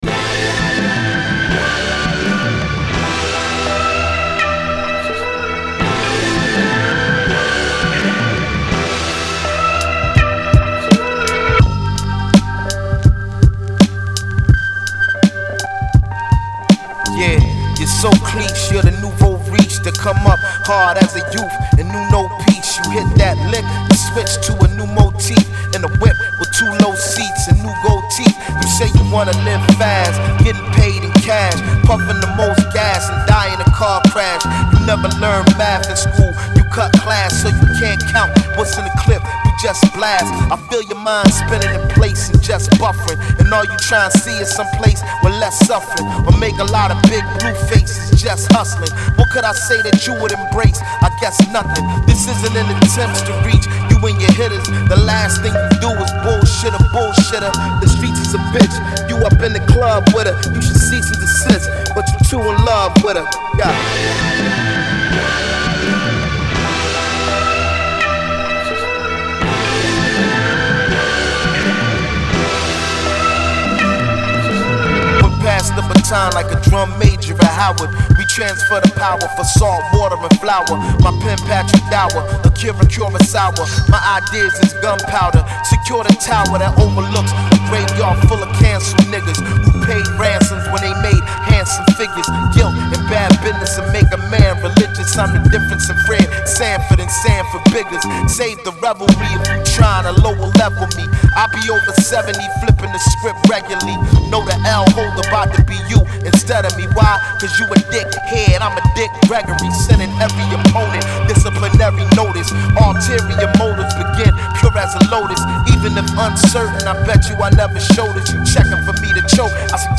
Genre : Hip-Hop, Rap